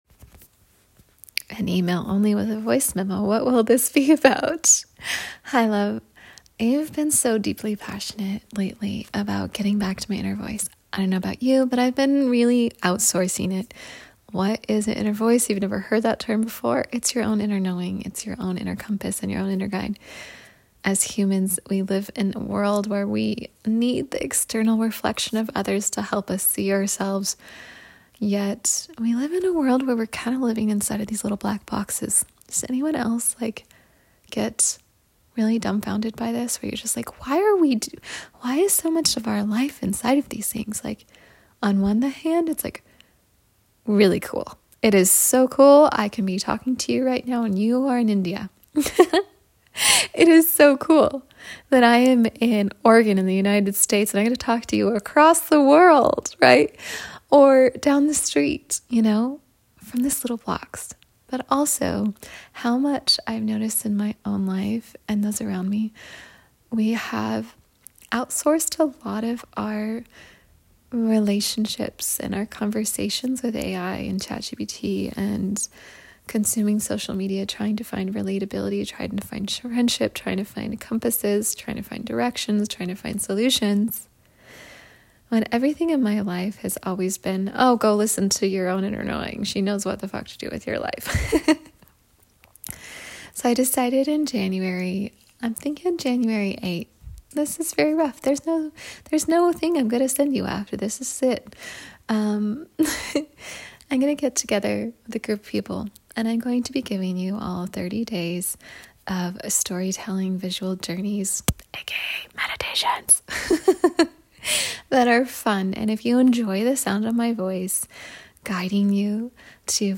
An email only with a voice memo.